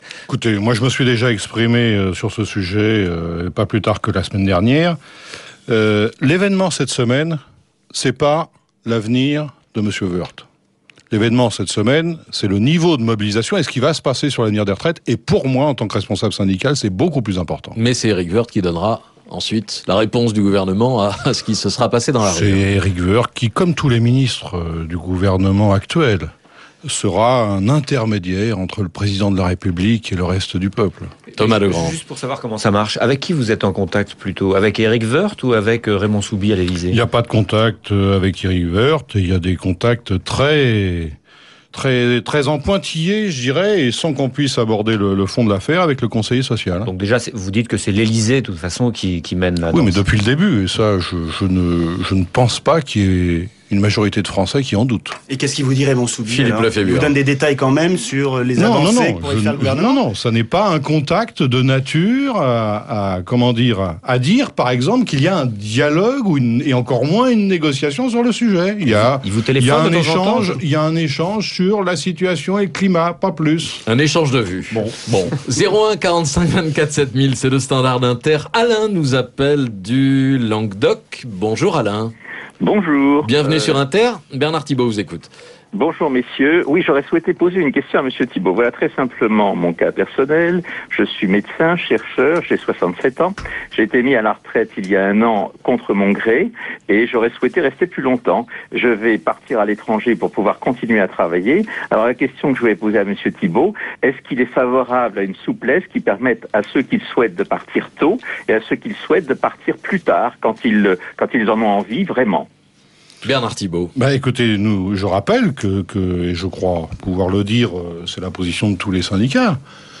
Retrouvez l’Invité d’Inter avec Bernard Thibault du 6 septembre 2010 :